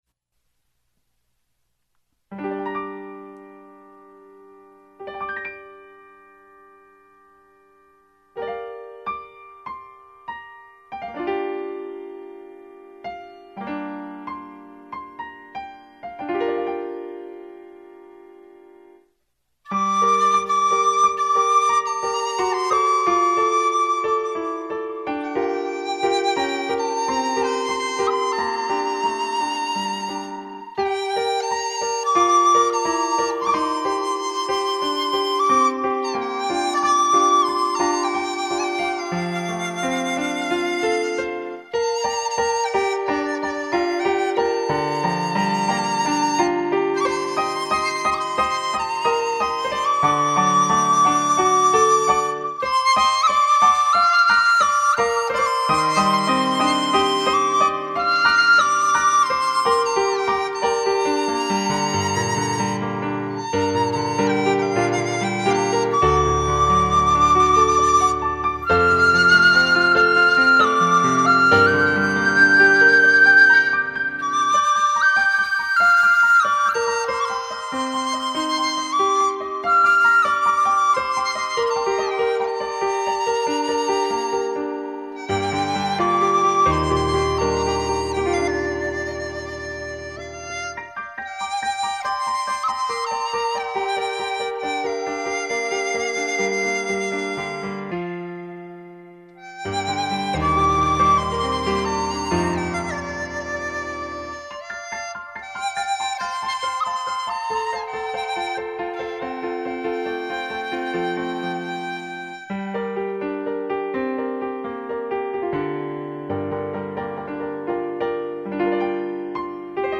两位老师的钢琴和笛声交织在一起行云流水！醉了！
熟悉的旋律，清脆动人的笛声与优美流畅的琴声交织成美妙乐曲！
浑然天成的钢琴和笛声，太喜欢了了！